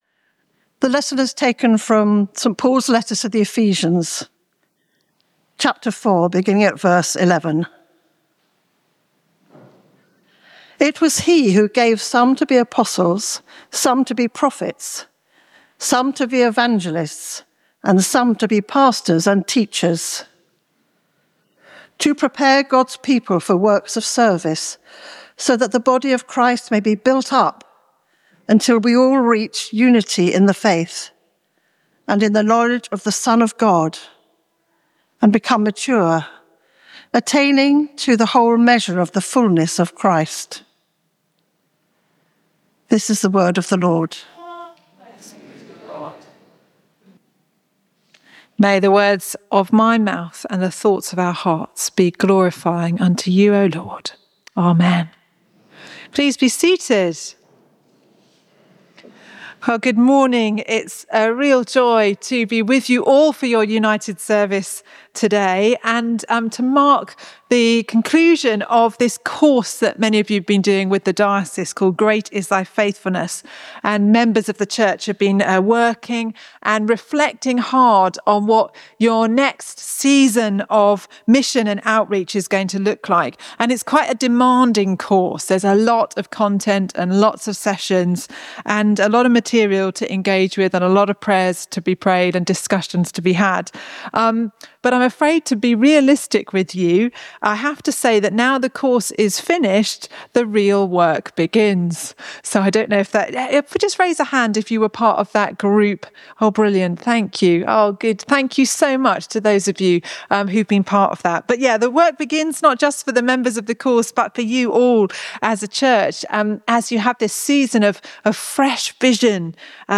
Ephesians 1:17-23 Service Type: Morning Worship